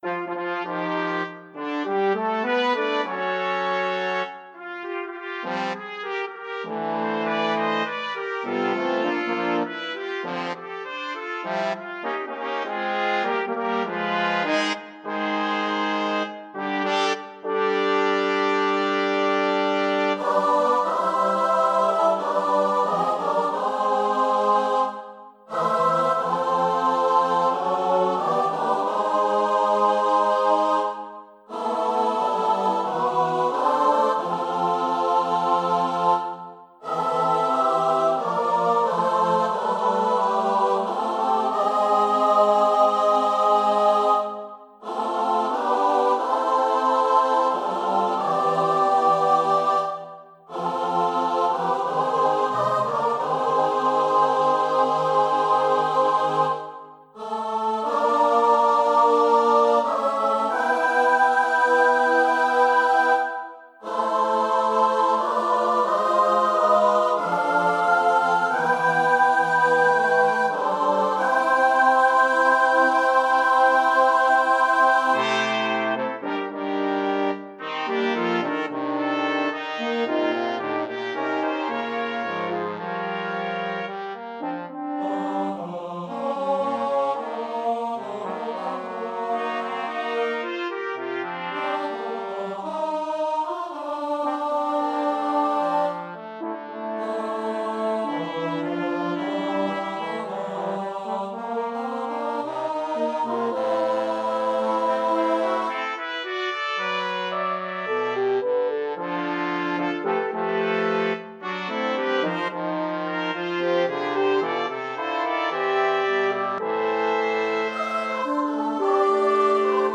for SATB chorus, Soprano and Tenor solos,